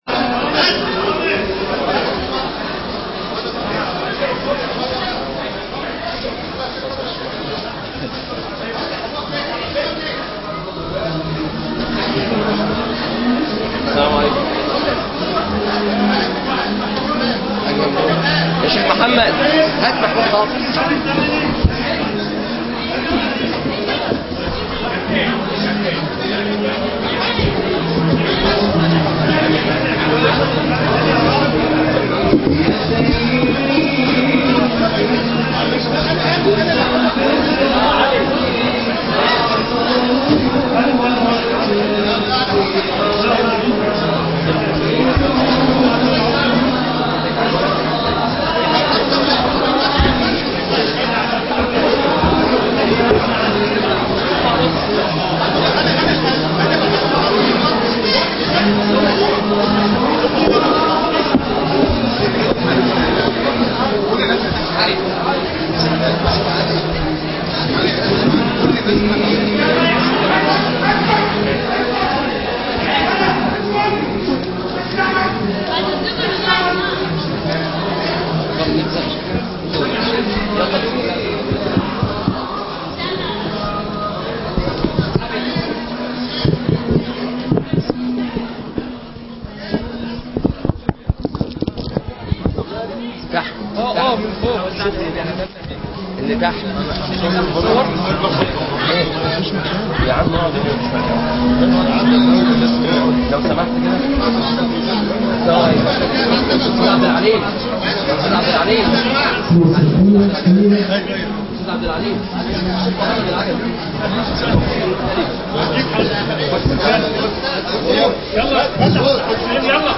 ندوة رائعة
بنادي الحوار بالمنصورة